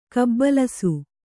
♪ kabbalasu